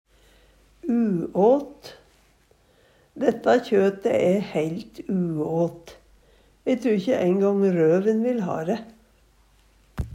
uåt - Numedalsmål (en-US)